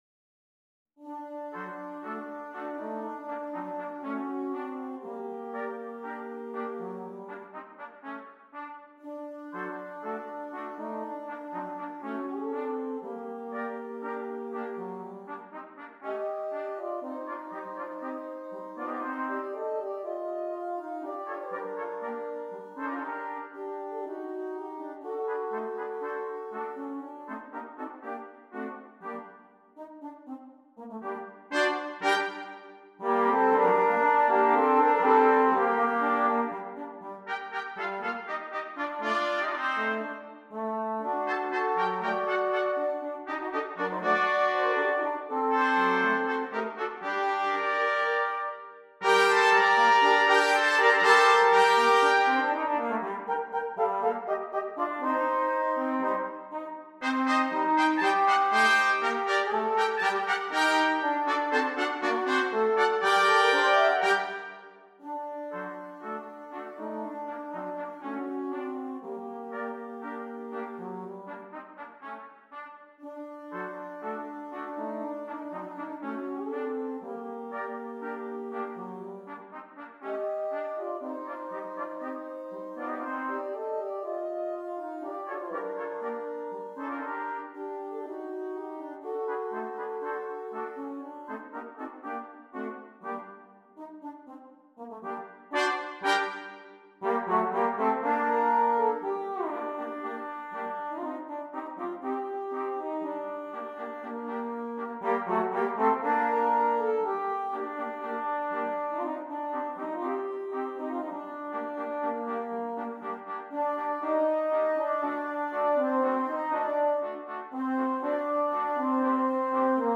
Brass Band
6 Trumpets